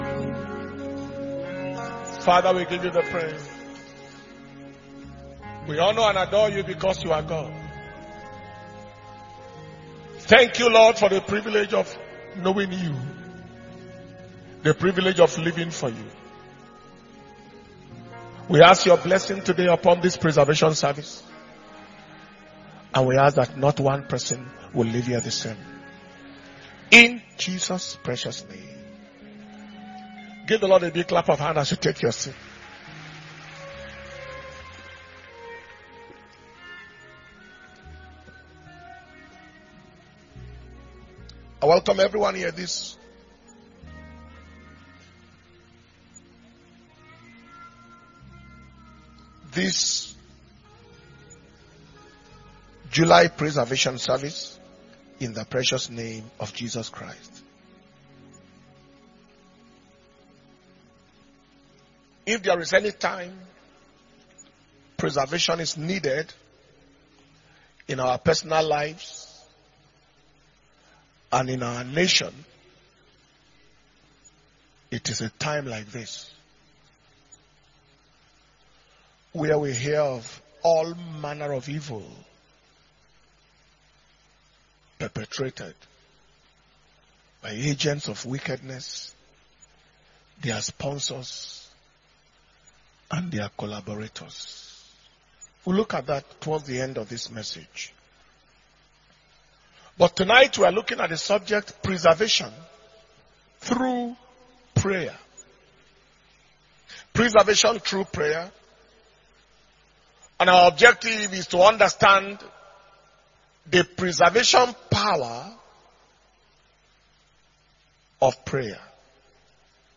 July 2022 Preservation And Power Communion Service